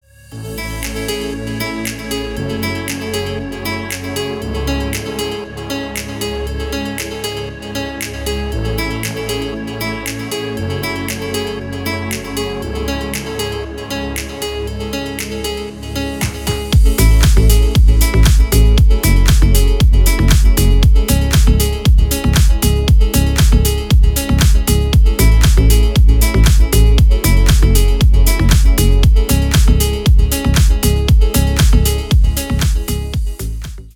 deep house
заводные
спокойные
без слов
расслабляющие
relax